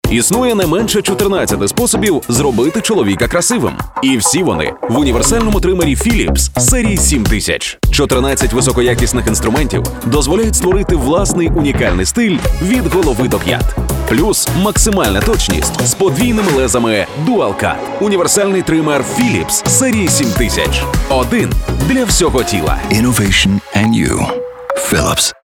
Але переміг емоційний ролик з  ВДВІЧІ  кращим результатом — CTR 1,7%.